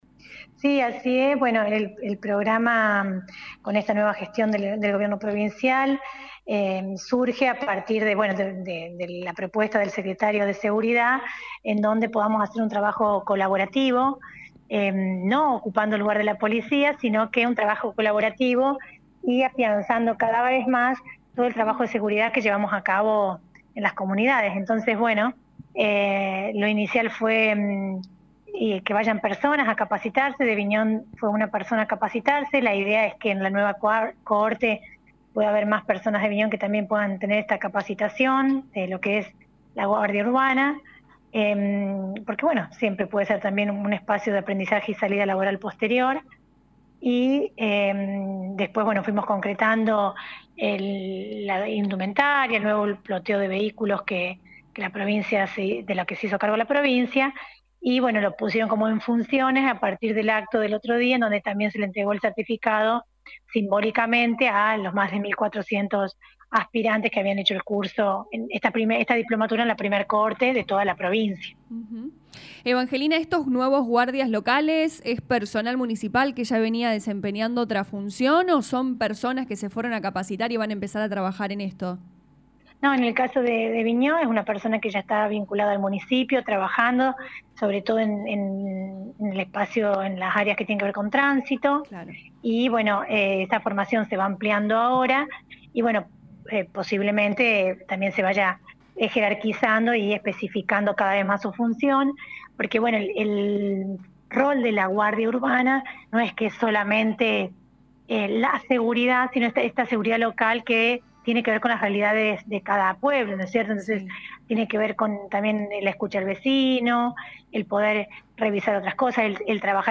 La intendente de Colonia Vignaud Lic. Evangelina Vigna dialogó con LA RADIO 102.9 FM y explicó cómo implementarán en la vecina localidad el Programa Provincial de Guardias Locales.